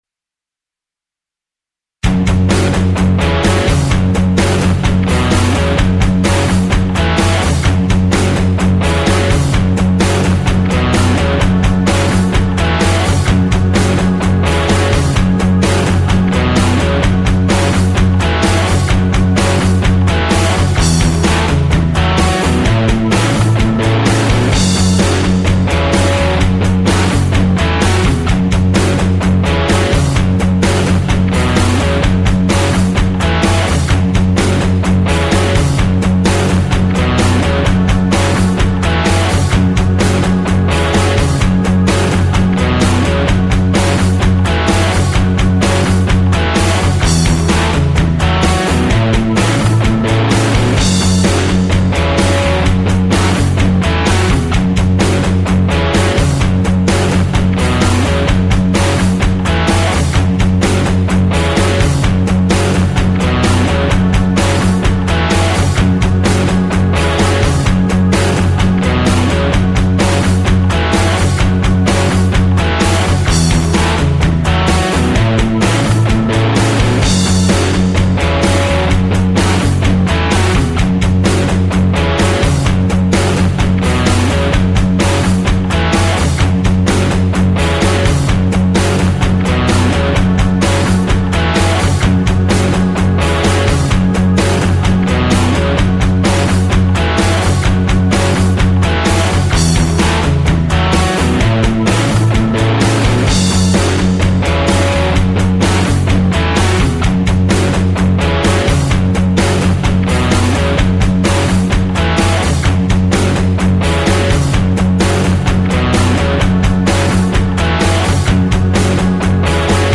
Hard Rock F#m.....